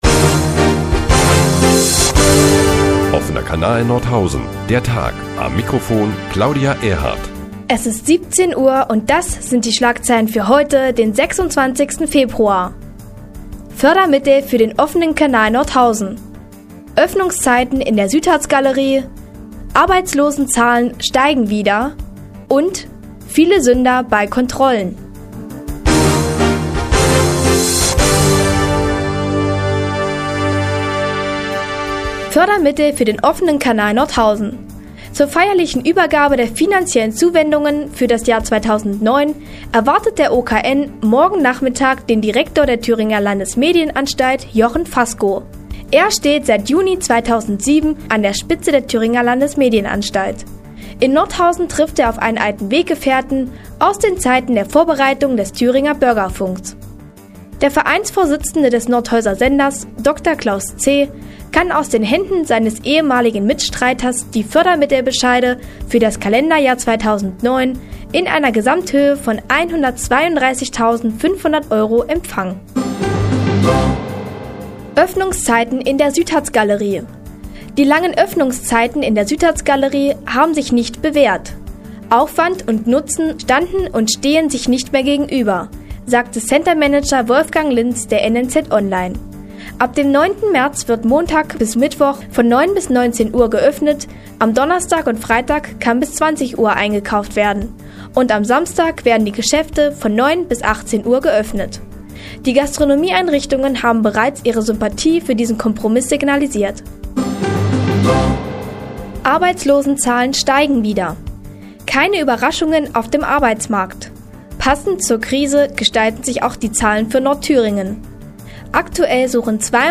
Die tägliche Nachrichtensendung des OKN ist nun auch in der nnz zu hören. Heute unter anderem mit der Übergabe der Fördermittel an den OKN und den neuen Öffnungszeiten der Südharz Galerie.